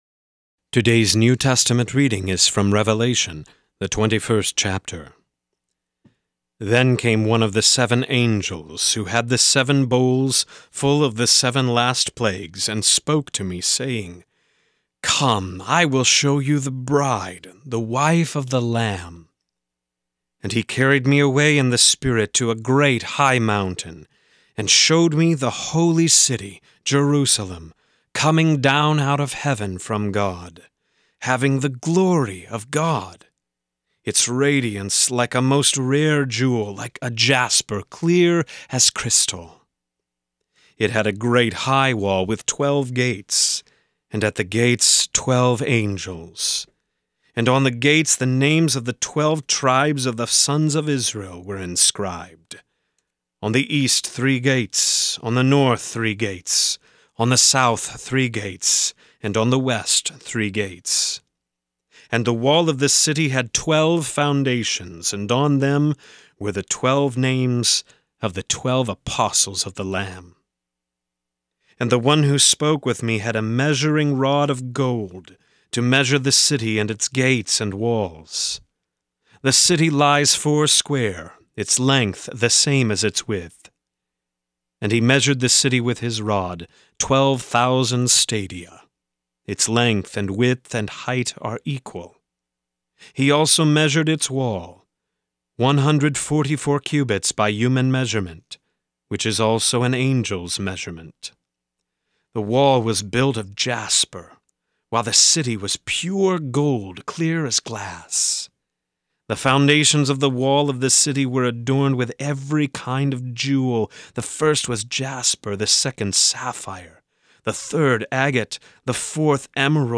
Morning Prayer Sermonette: Revelation 21:19-27
This is a rebroadcast from November 25, 2016.